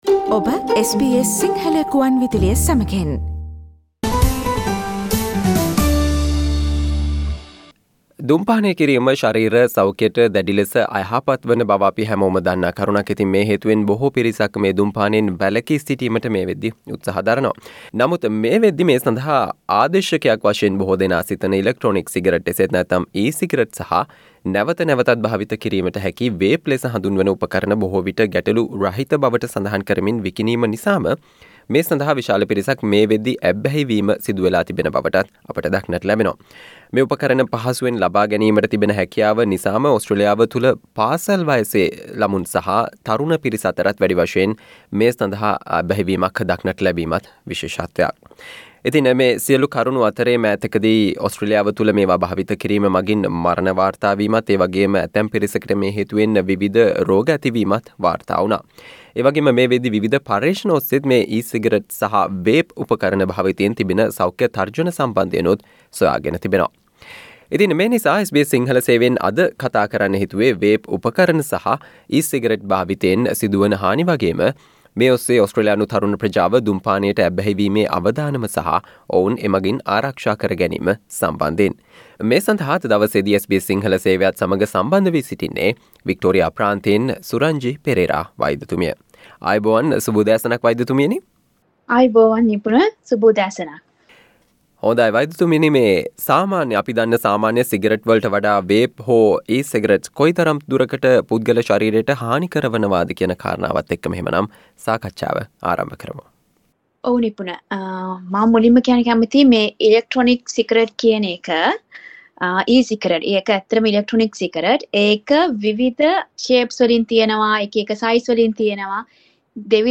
සිගරට් සඳහා ආදේශකයක් වශයෙන් බොහෝ දෙනා සිතන e-cigarettes සහ නැවත නැවතත් භාවිත කිරීමට හැකි Vape ලෙස හඳුන්වන උපකරණ භාවිතයෙන් සිදු වන හානි සහ එම හානියෙන් ඔබේ දරුවා අරක්ෂා කරගන්නේ කෙසේද යන්න පිළිබඳව වන සාකච්චාවට සවන්දෙන්න